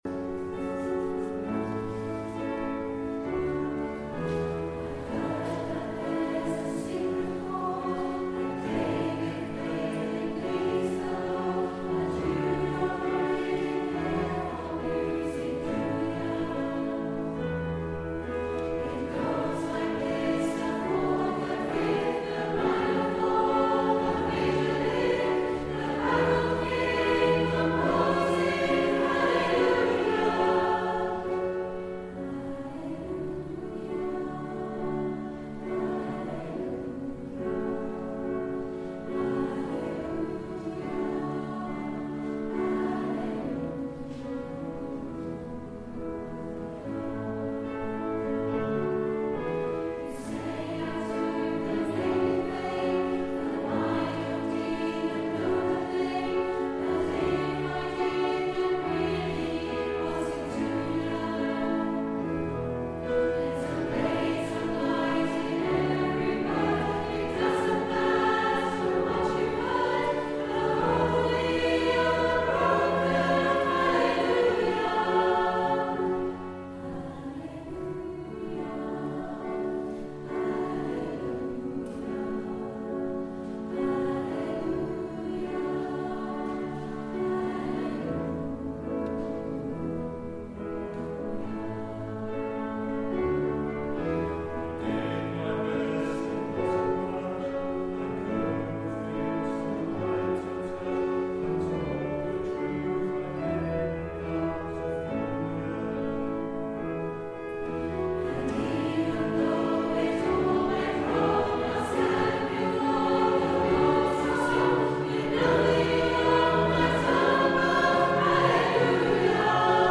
At the Simon Balle Choral Concert 2017